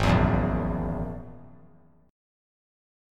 G#7sus2#5 Chord
Listen to G#7sus2#5 strummed